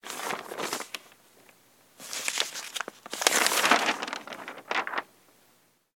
newspaper.ogg